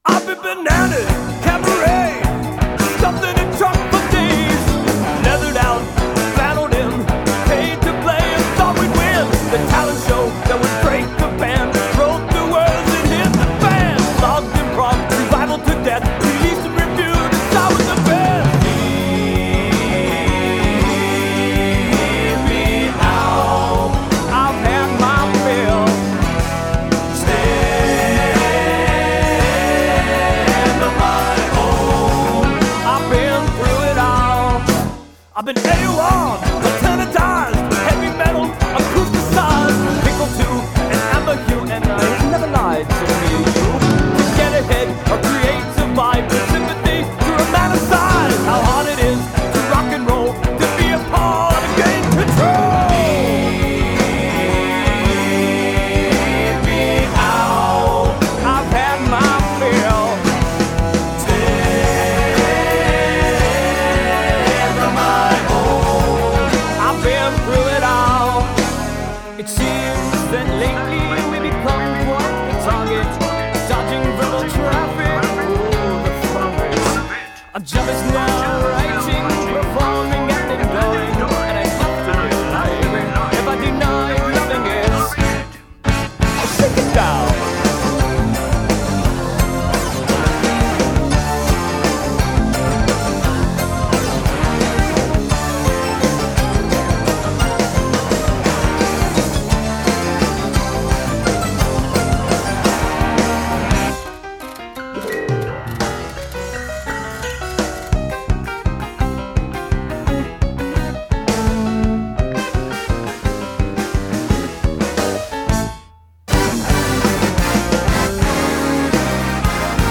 keyboards, backing vocals
bass, midi pedals
guitars, lead and backing vocals
drums and percussion
lead and backing vocals